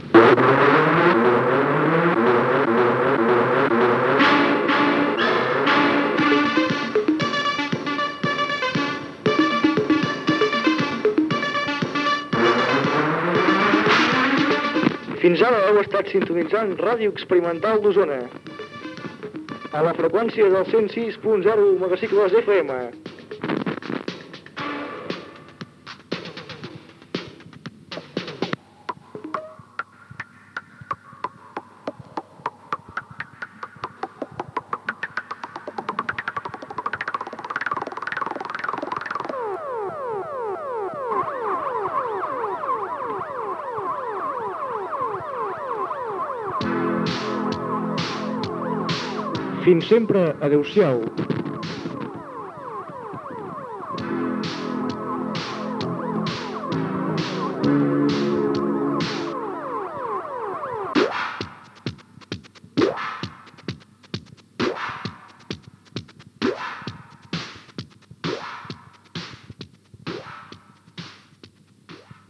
Tancament de l'emissió, amb la identificació, freqüència i el comiat
FM